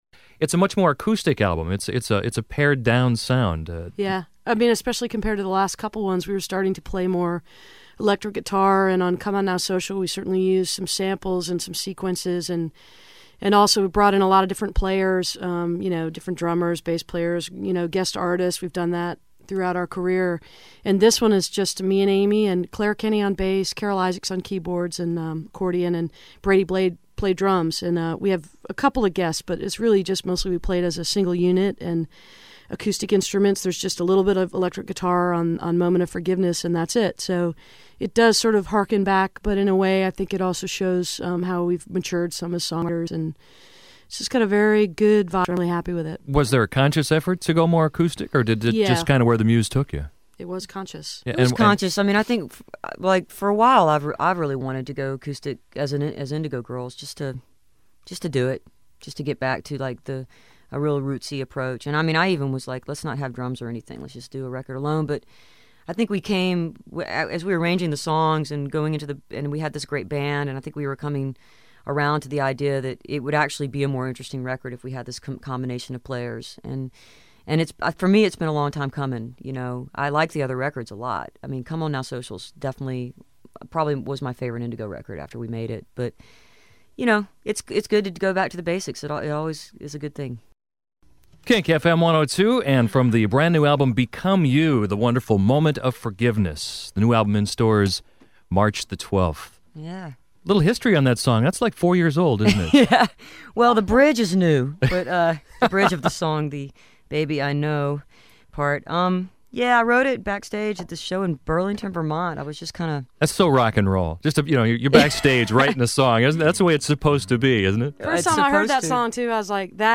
01. interview